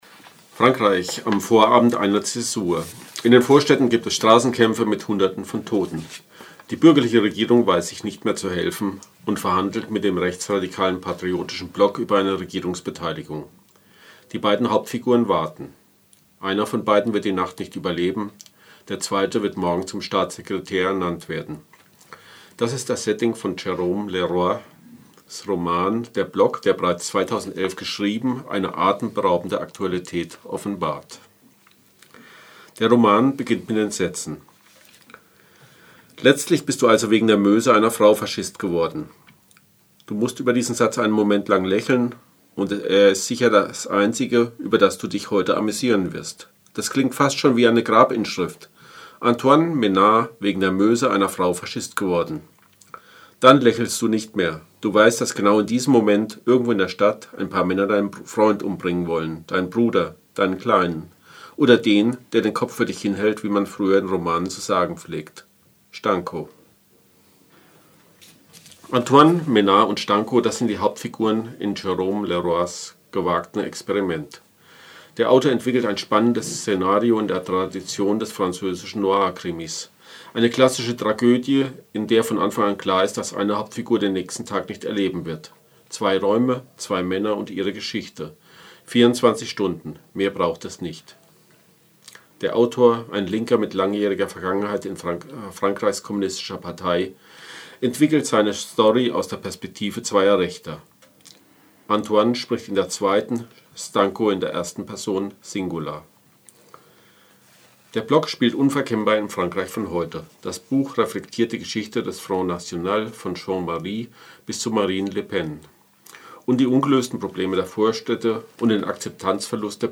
In Frankreich sind Mitte Oktober insgesamt 10 Menschen aus dem rechtsradikalen Milieu, aus dem Umfeld der „Action Francaise“ wegen des Vorwurfs der Vorbereitung von Anschlägen gegen PolitkerInnen, Moscheen und Geflüchtete festgenommen worden. Über die Hintergründe und die Verbindungen zum Front National sprachen wir mit unserem Frankreichkorrespondenten